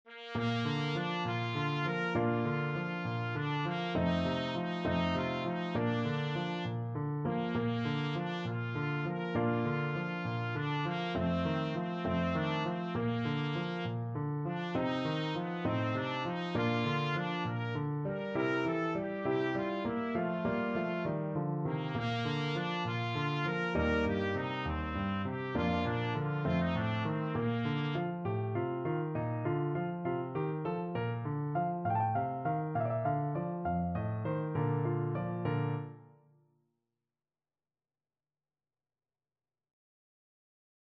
Trumpet
~ = 100 Fršhlich
Bb major (Sounding Pitch) C major (Trumpet in Bb) (View more Bb major Music for Trumpet )
6/8 (View more 6/8 Music)
Classical (View more Classical Trumpet Music)